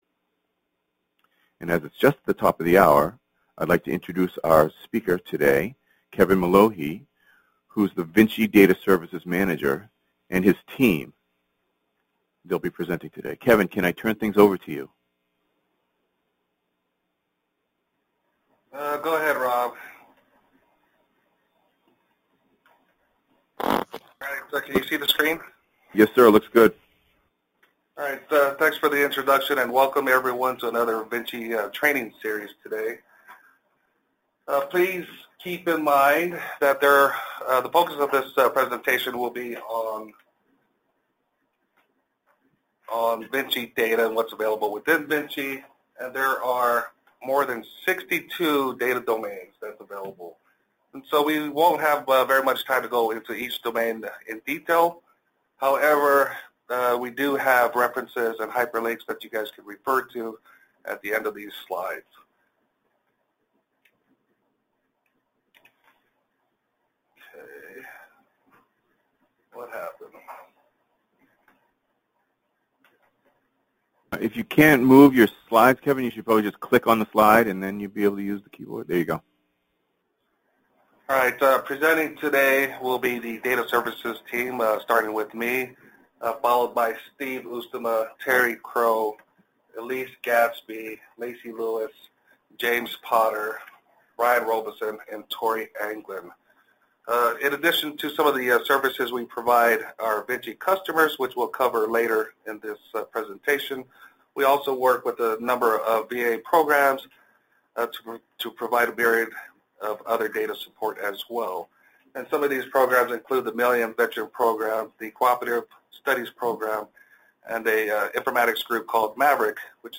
Description: This webinar will address data available in VINCI and data services provided by the VINCI Data Team staff. Intended Audience: Those with some prior familiarity with VA medical data.